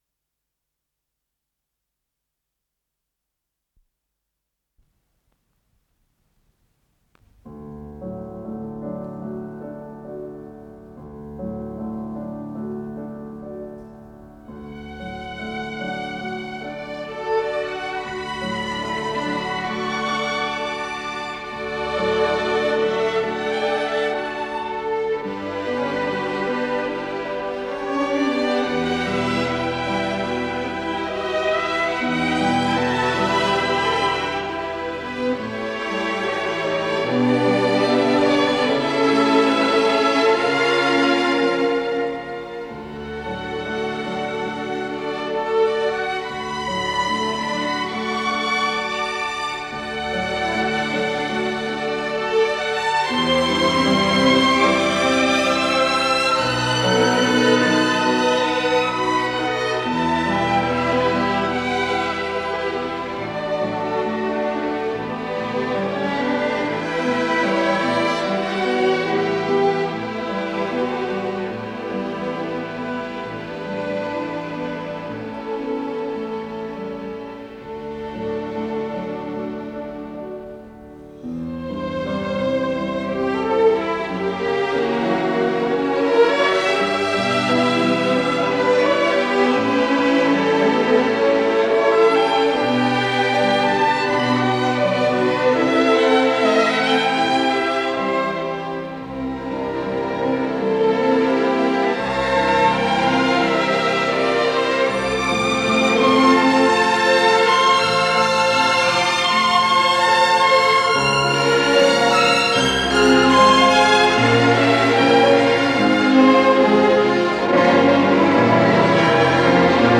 с профессиональной магнитной ленты
ПодзаголовокИнтермеццо из 2-го действия оперы "ТАИС" (оригинал - для оркестра), соч. 1894г.
ИсполнителиАнсамбль скрипачей Государственного Академического Большого театра СССР
ВариантДубль моно